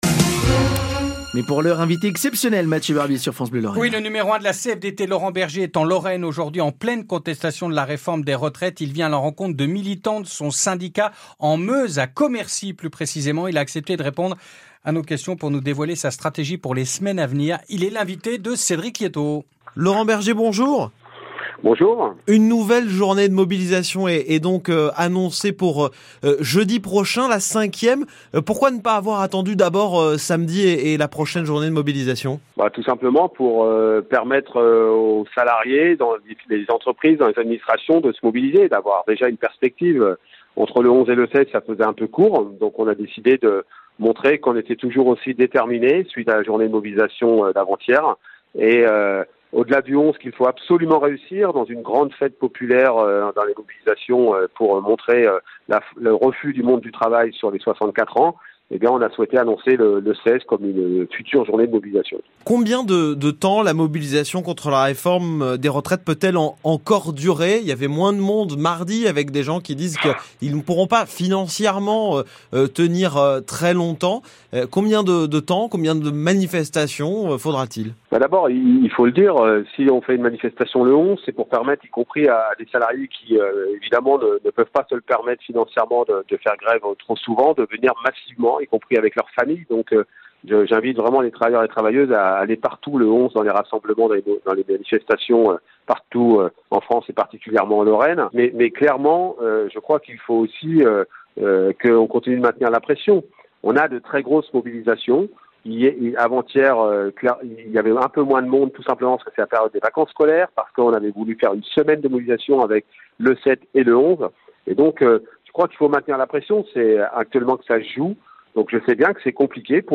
interview_laurent_berger_fevrier_2023.mp3